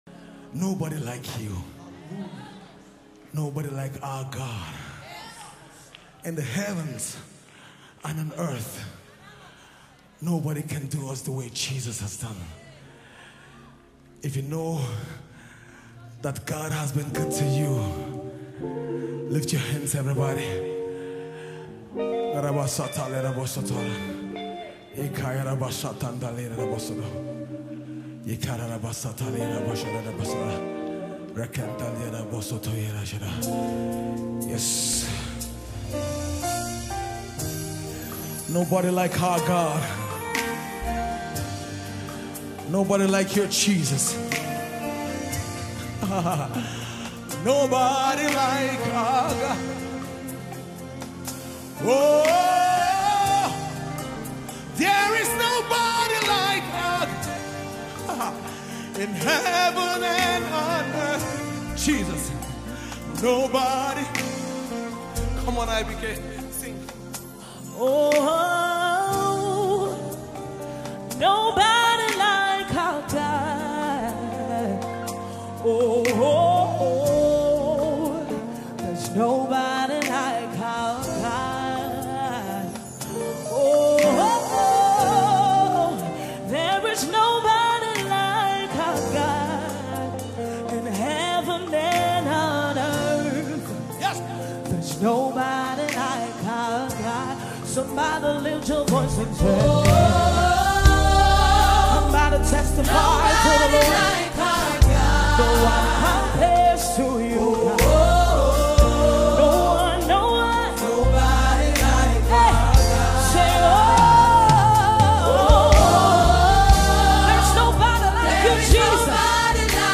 Renowned Gospel Artist